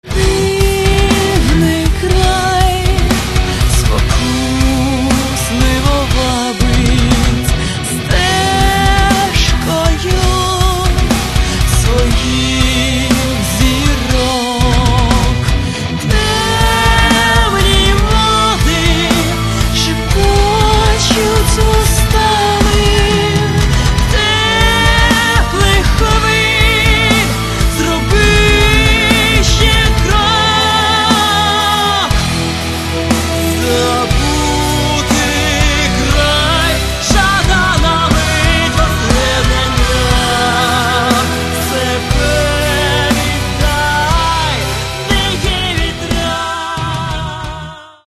Каталог -> Рок та альтернатива -> Енергійний рок
Альтернативна музика?
Мелодійний хеві-метал?